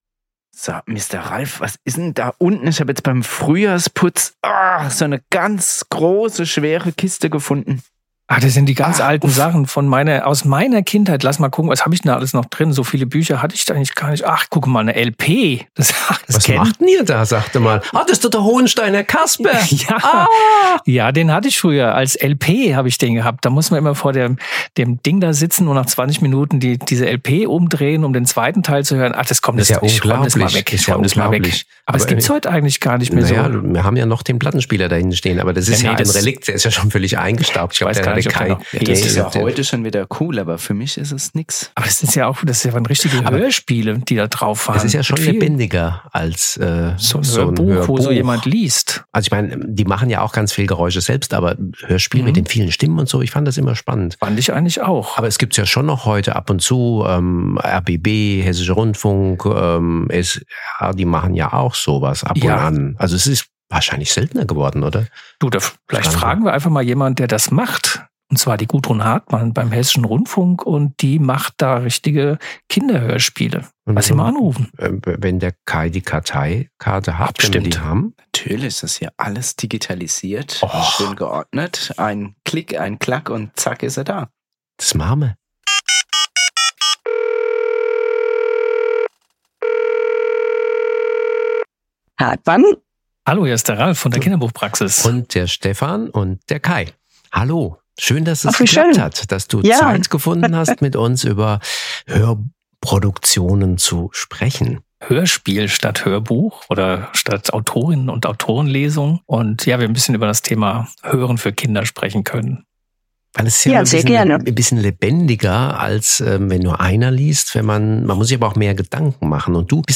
Ein spannender Austausch